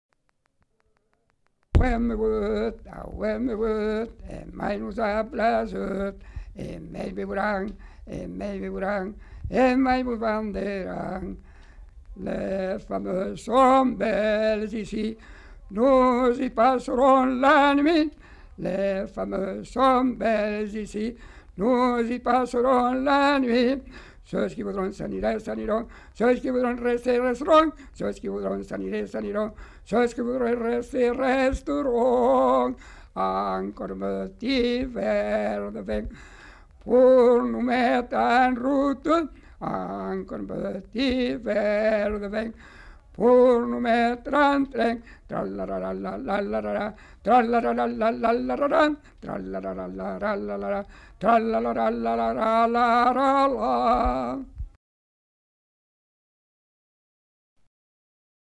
Genre : chant
Effectif : 1
Type de voix : voix d'homme
Production du son : chanté
Danse : varsovienne